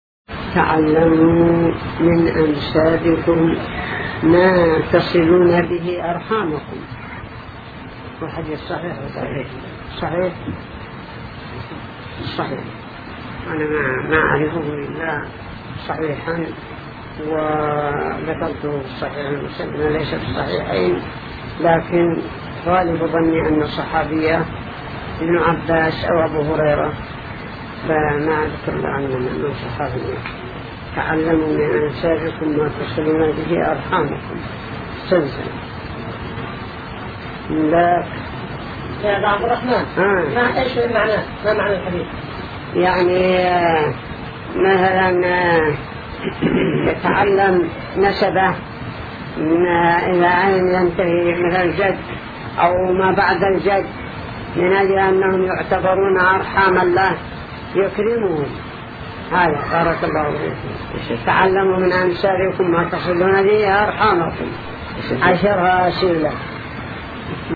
تعلموا من أنسابكم ما تصلوا به أرحامكم | فتاوى الشيخ مقبل بن هادي الوادعي رحمه الله